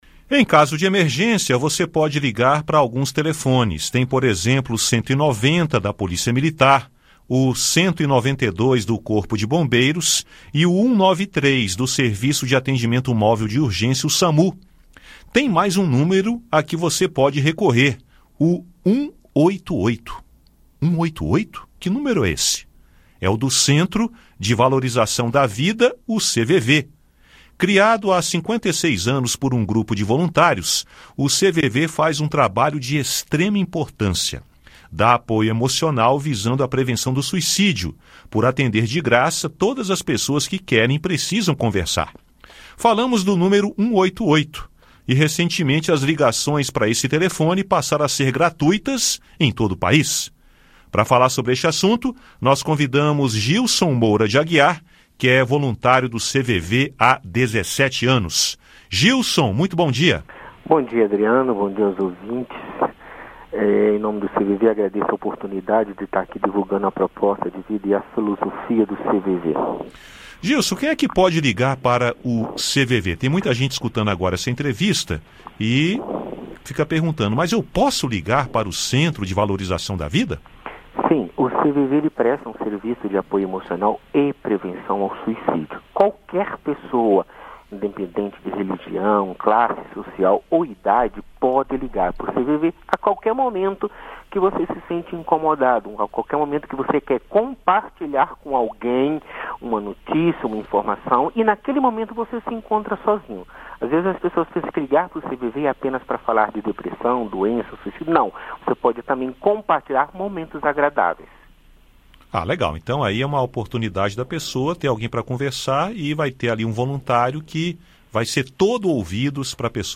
Voluntário explica